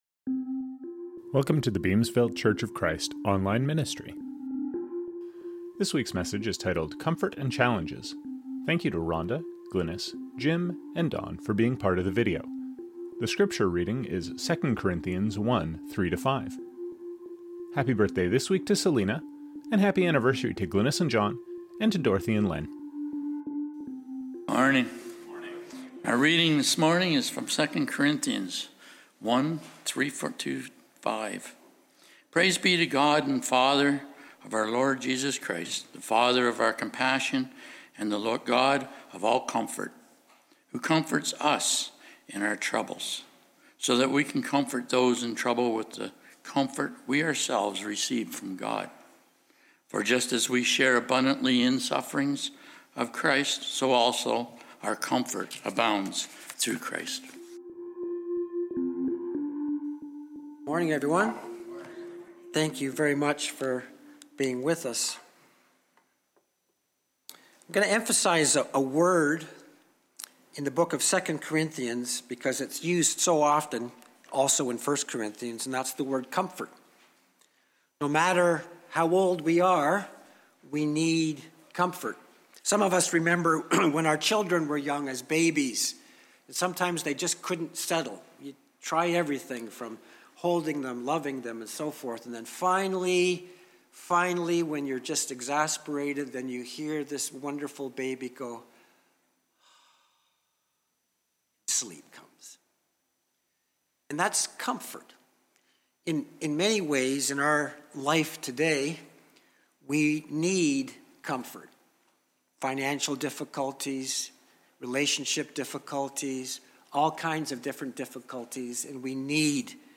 Sermon – 2 Corinthians 1:3-4